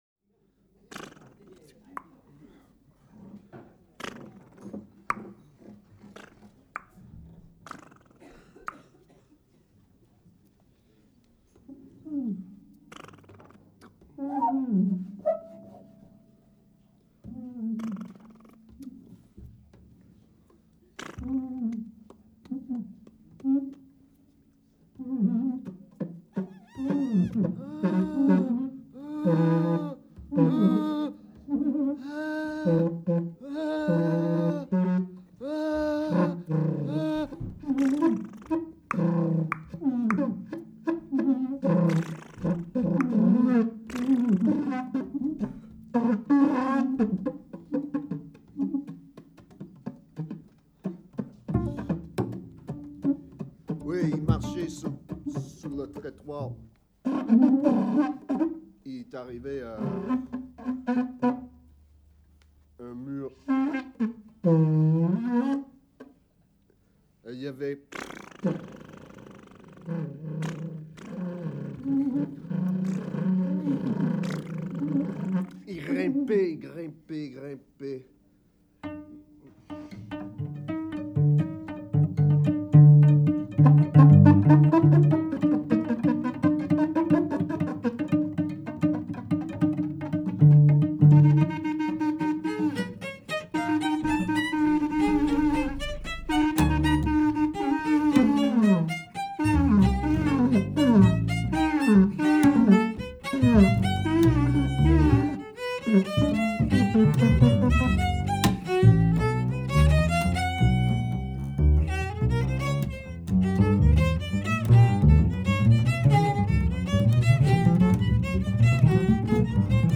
saxophone alto
violoncelle & voix
contrebasse
Improv
Paris, 28 rue Dunois, 9 mai 1982